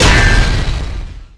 bladeslice5.wav